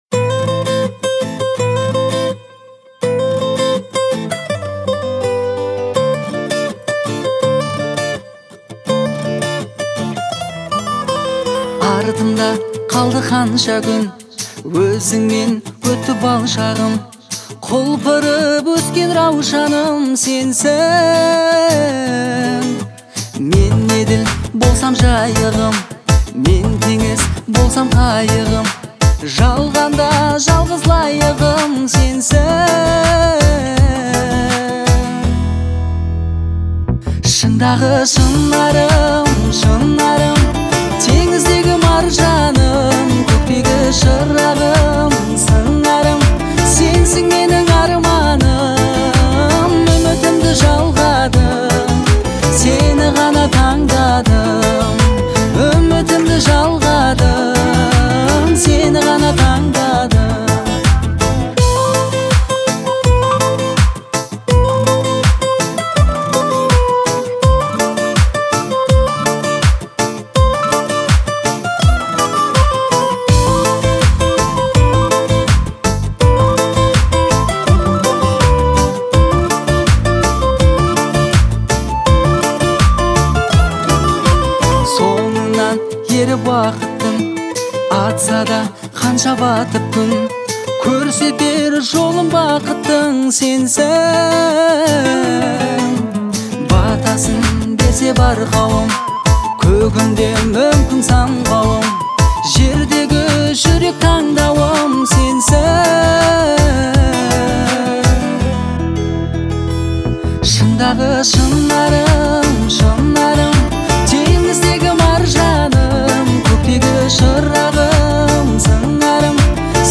это трек в жанре поп с элементами R&B
эмоциональным исполнением и мелодичным вокалом